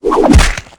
bash1.ogg